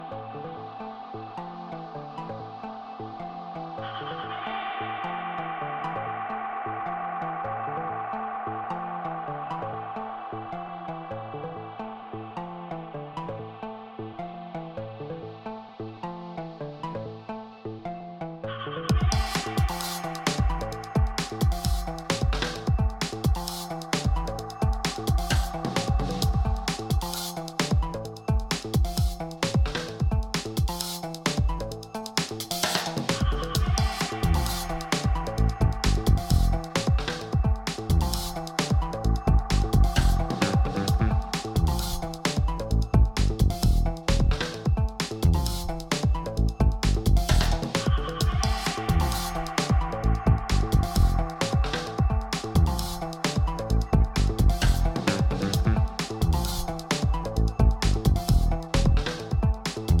ジャジー・ブロークン・エレクトロな